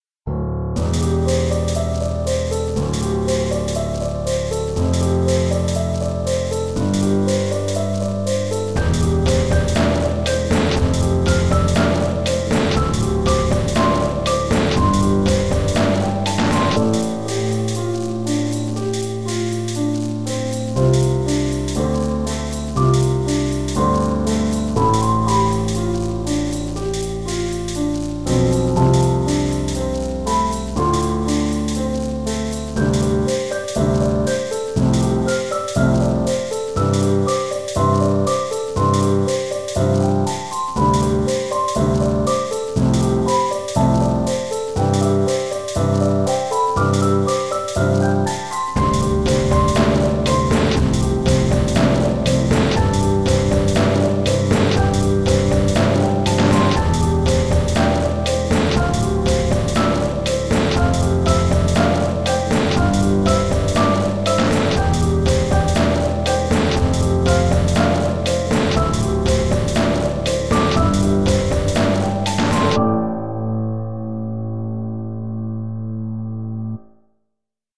tone poem
It features piano and percussion. It was written, recorded, and mastered in Reason 2.5, using various refills.